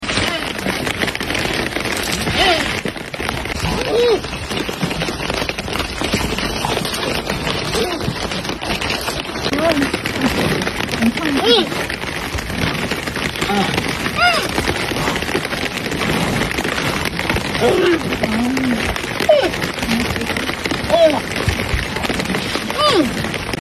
A group of giant pandas sound effects free download
A group of giant pandas making a "Yingying" sound are fighting.